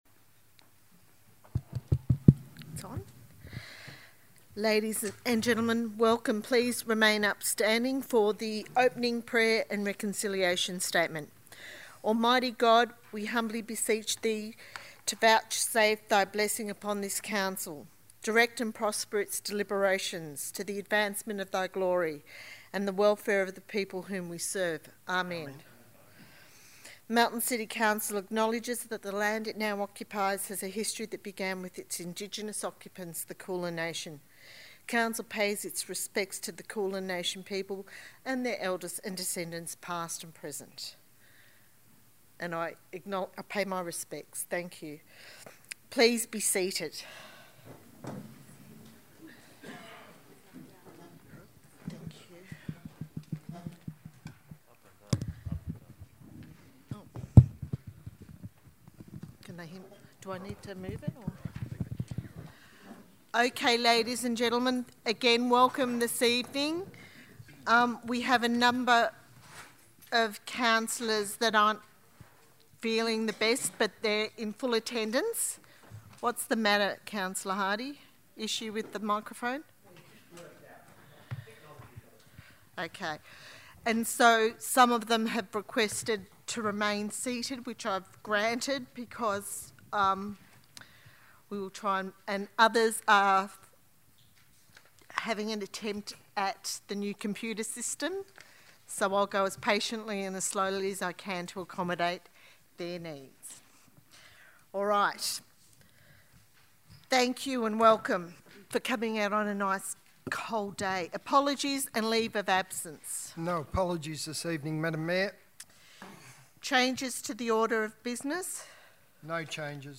26 June 2017 - Ordinary Council Meeting